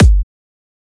edm-kick-16.wav